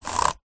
Пальцы бегут по клавишам компьютерной клавиатуры с Bluetooth.
paltsy-begut-po-klavisham-kompiuternoi-klaviatury-s-bluetooth.mp3